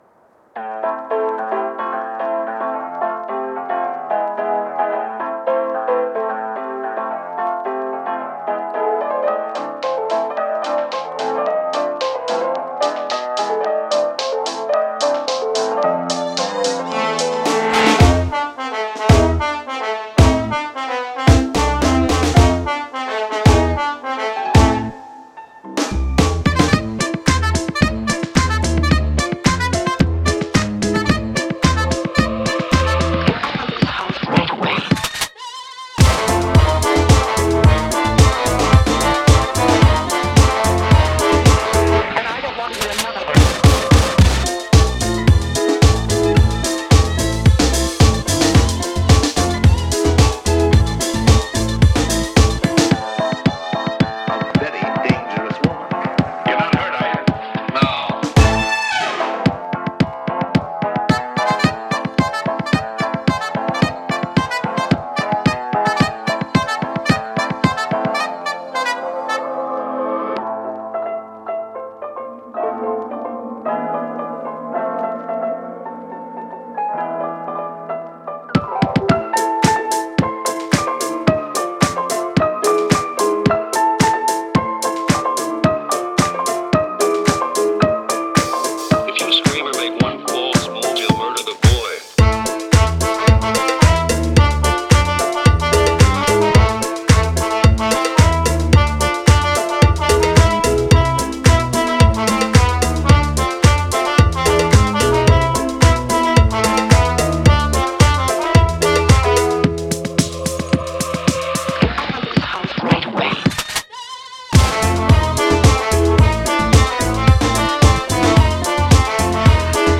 punchy